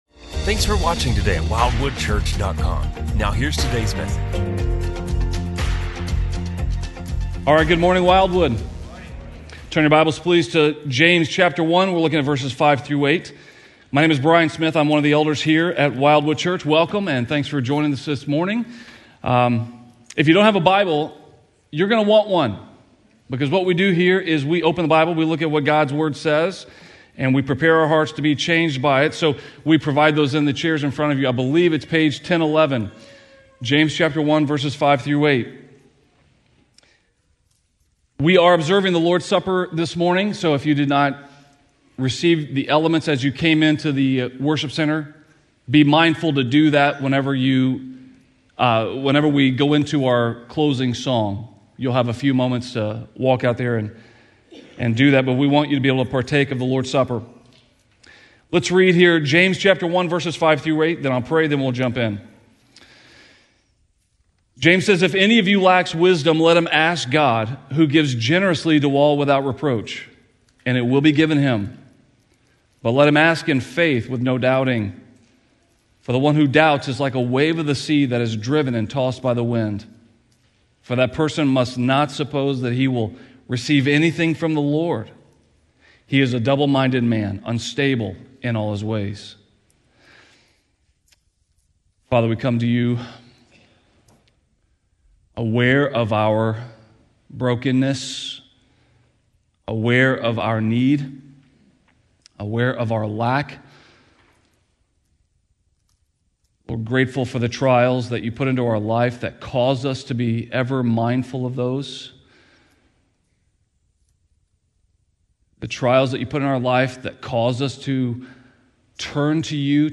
A message from the series "Wisdom From Above." We are not in control of our destiny as the world would have us believe. Rather God is sovereign over all aspects of our lives.